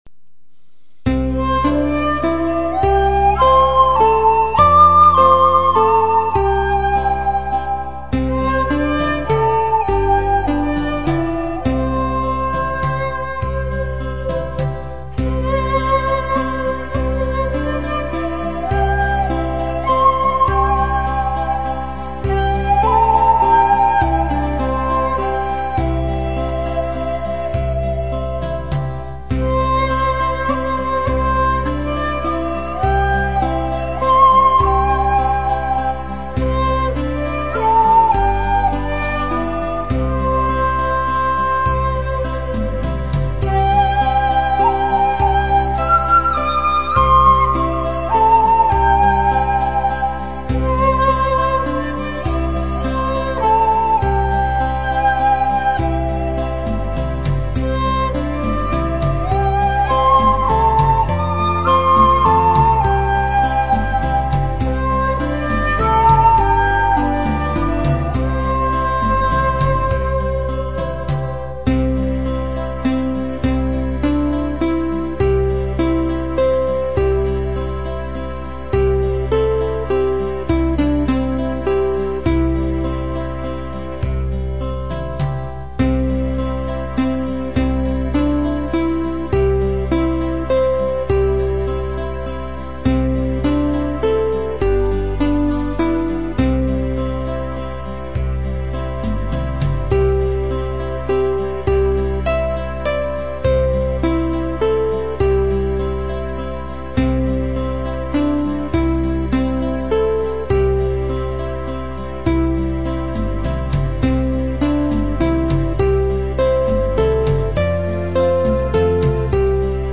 以下の曲には簡単な伴奏が付いていますから、楽しく吹いて正しいリズム感と音感を養ってください。
尺八演奏が、原則的に１コーラス毎に有／無のパターンになっています。
ゴンドラの唄 ３コーラス　尺八/１尺６寸　童謡・唱歌のページに尺八三重奏があります。